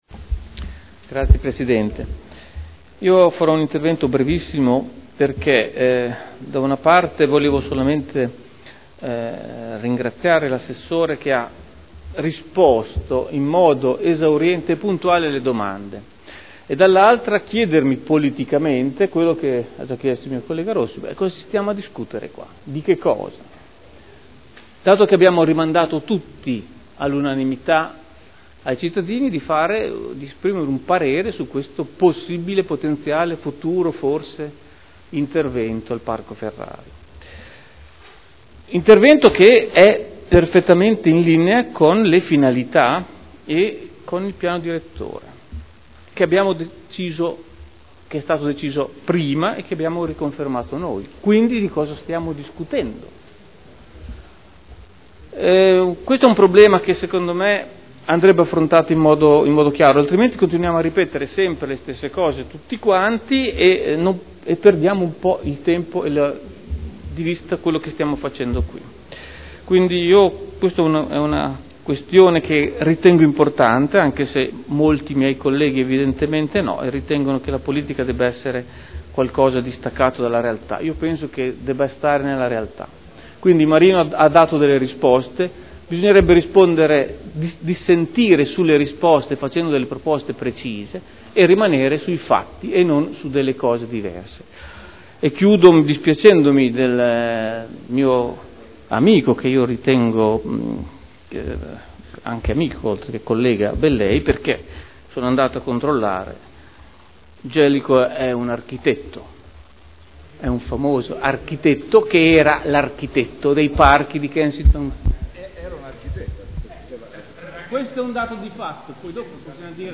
Seduta del 02/05/2011.